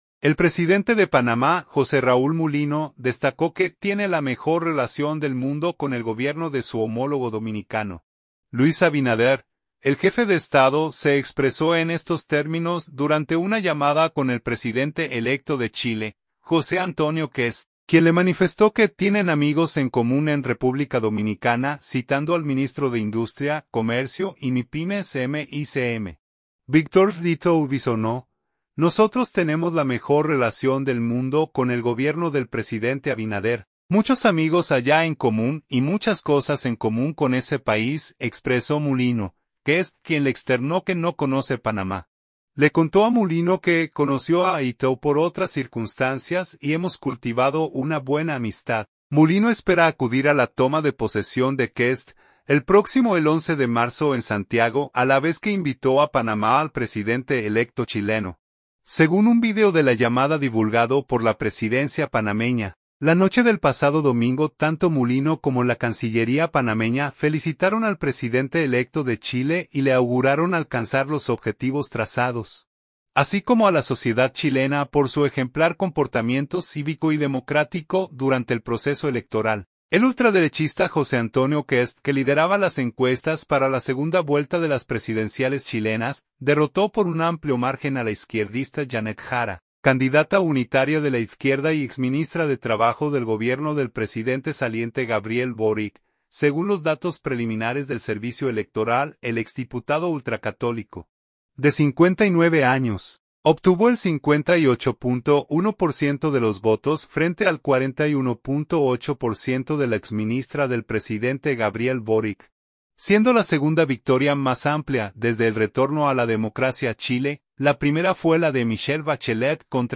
Se expresó en estos términos durante una llamada con el presidente electo de Chile, José Antonio Kast, a quien invitó a su país